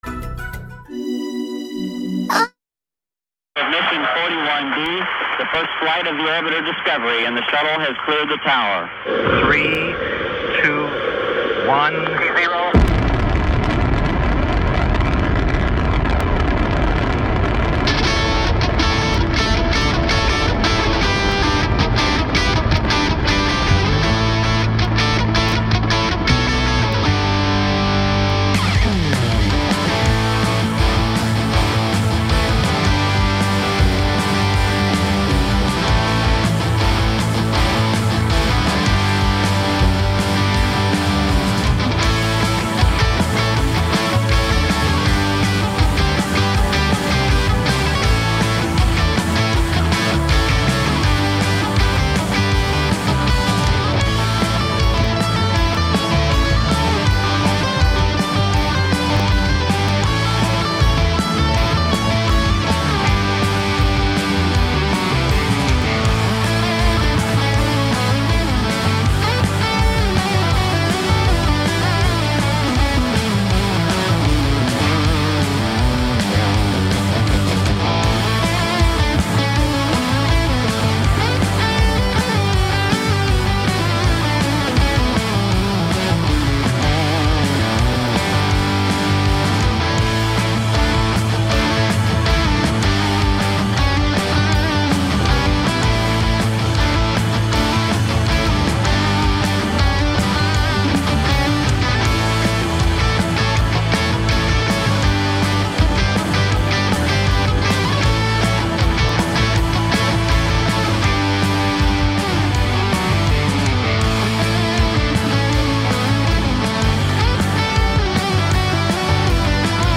Programa con la mejor musica rock